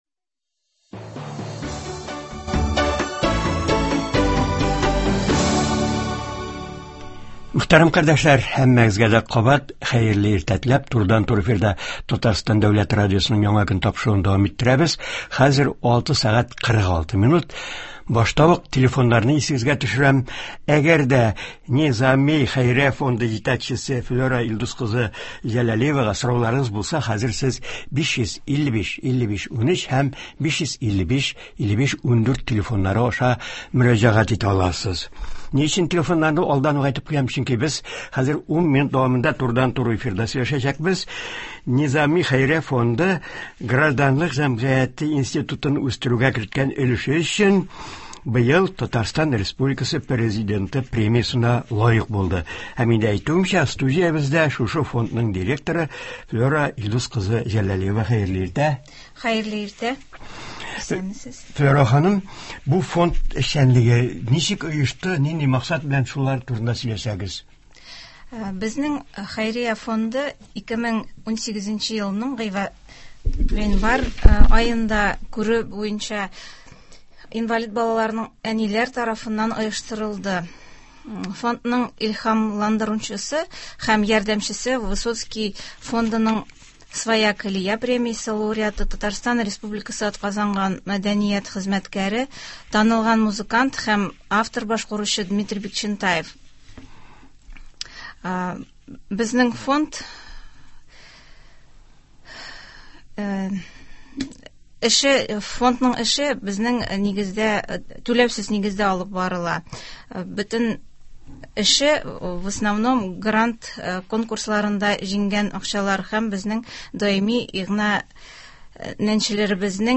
Актуаль интервью (07.12.20)